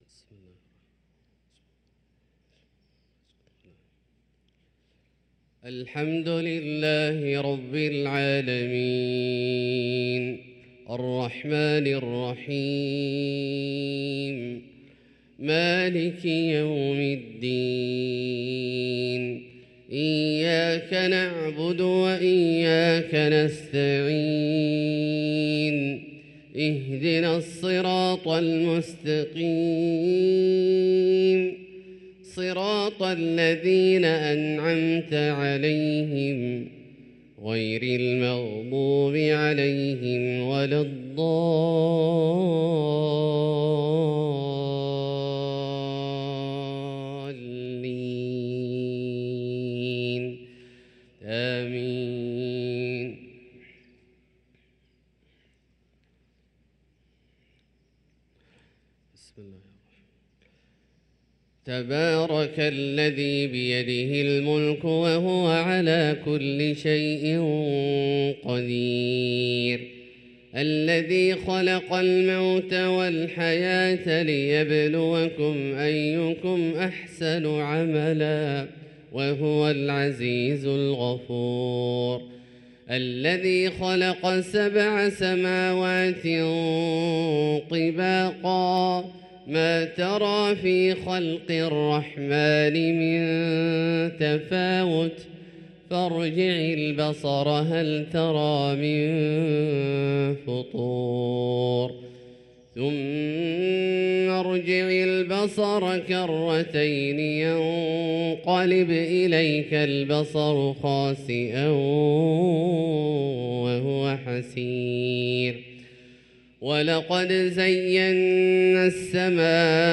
صلاة الفجر للقارئ عبدالله الجهني 10 ربيع الأول 1445 هـ
تِلَاوَات الْحَرَمَيْن .